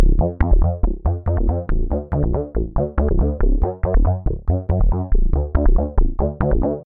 Cube 滚动合成器低音
描述：滚动的恍惚的低音合成器声音
Tag: 130 bpm Techno Loops Bass Synth Loops 1.24 MB wav Key : F